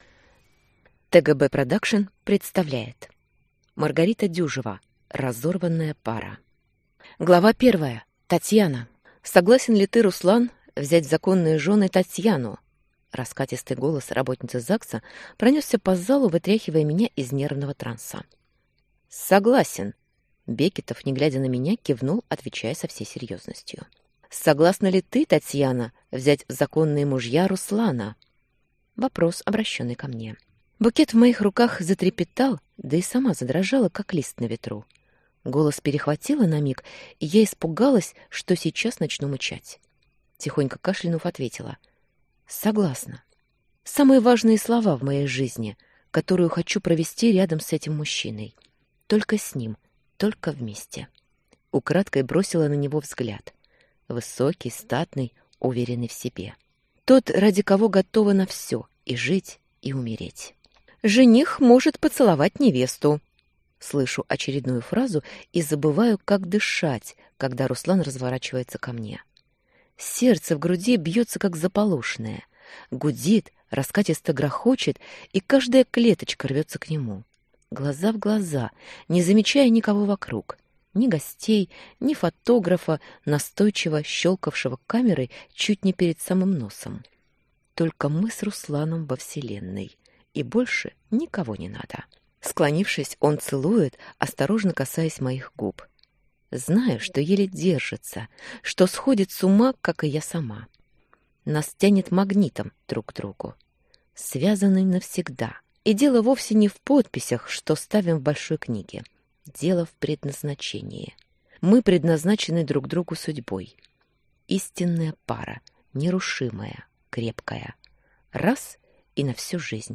Аудиокнига Разорванная пара | Библиотека аудиокниг